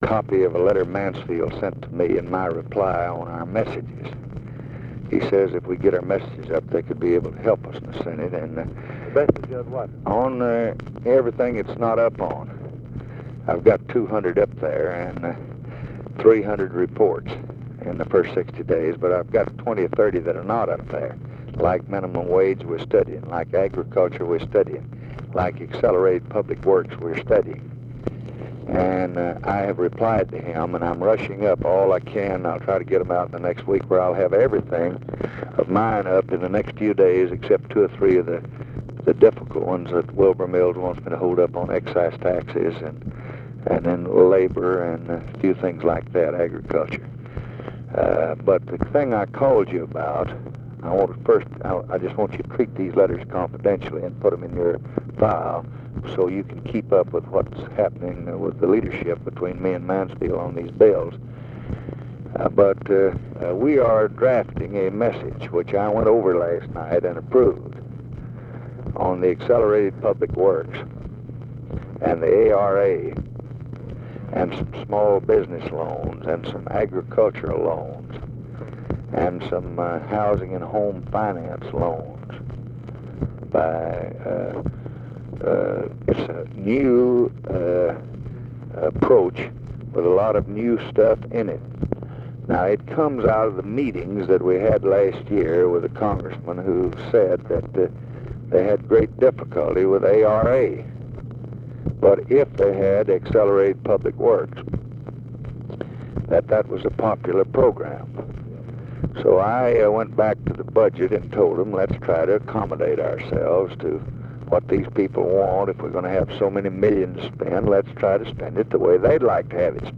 Conversation with JOHN MCCORMACK, March 25, 1965
Secret White House Tapes